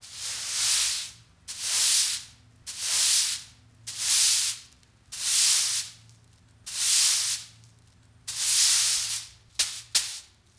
broom.wav